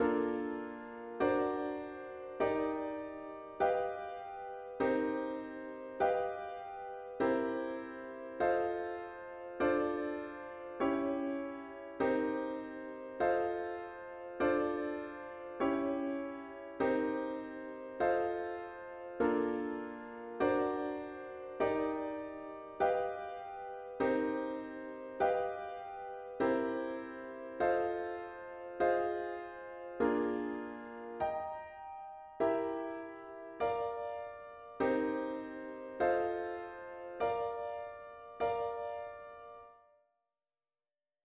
A chords only version of the score is here.